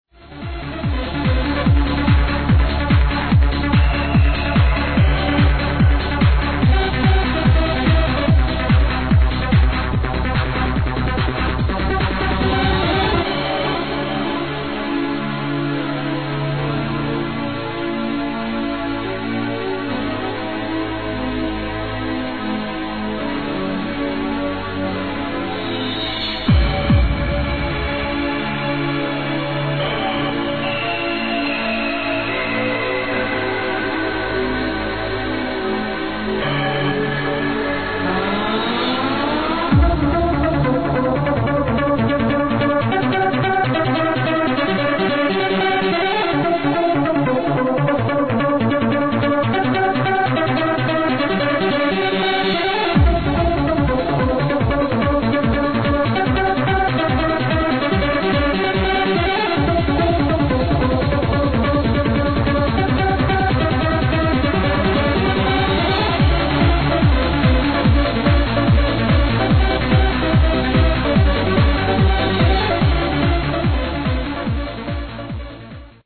the synths sounds like